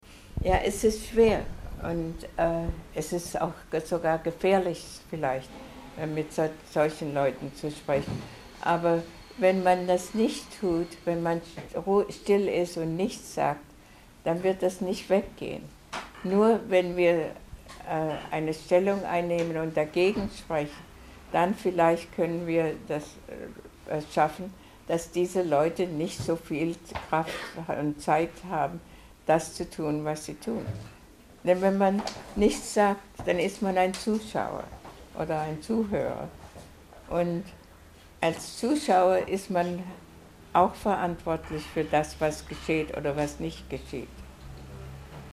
Die Gelegenheit, der Rednerin nach ihren Ausführungen noch Fragen zu stellen, wurde von den Jugendlichen ausgiebig in Anspruch genommen.